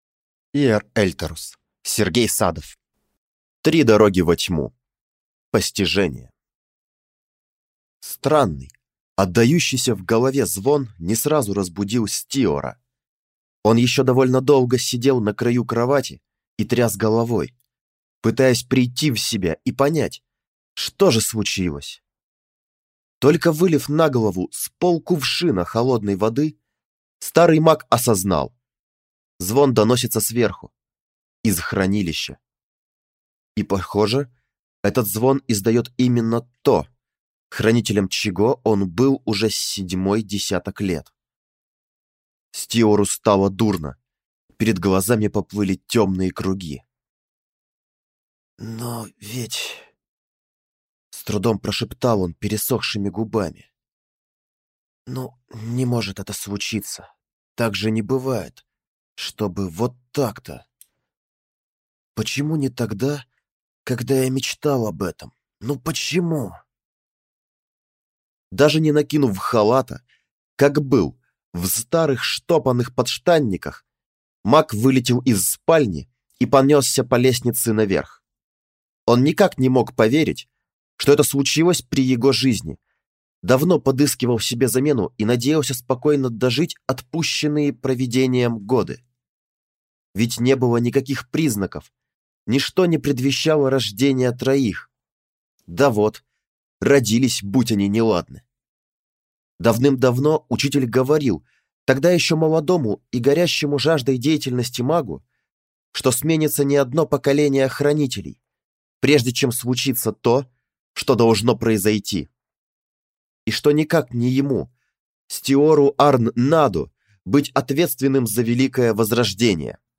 Аудиокнига Постижение | Библиотека аудиокниг